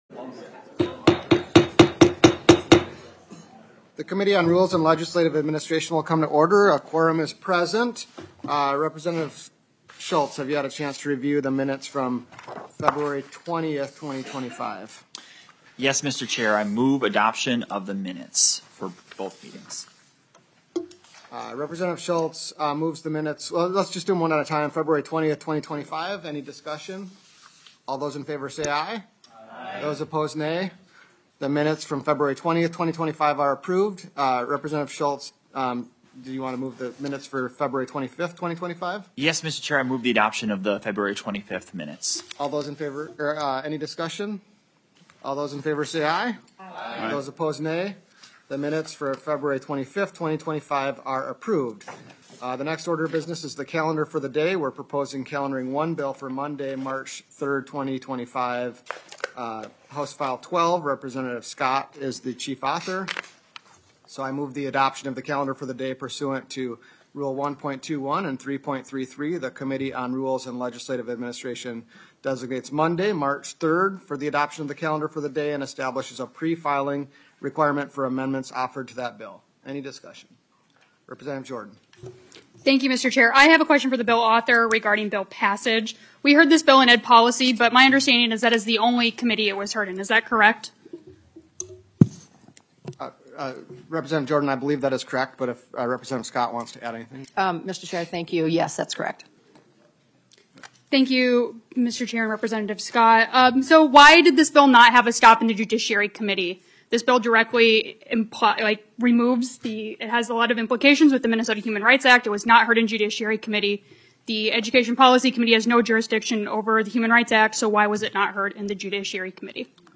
Majority Leader Niska, Chair of the Rules and Legislative Administration Committee, called the meeting to order at 10:06 A.M. on February 27th, 2025, in Room G23 of the State Capitol.